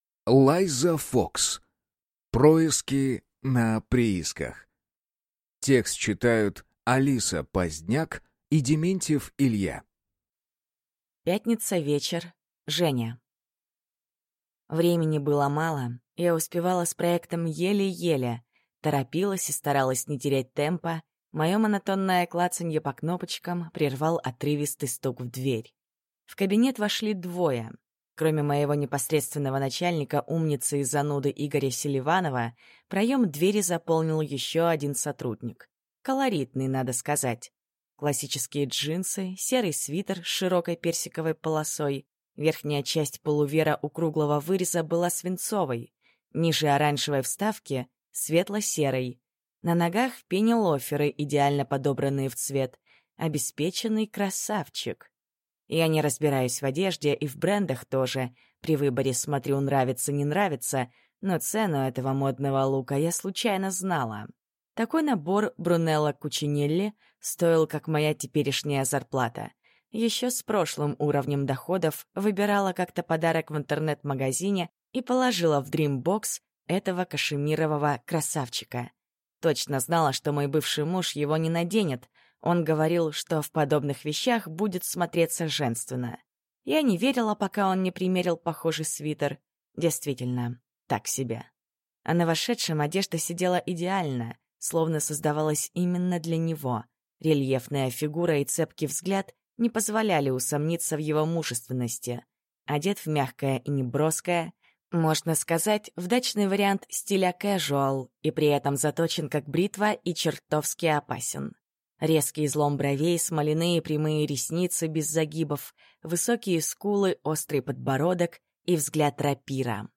Аудиокнига Происки на приисках | Библиотека аудиокниг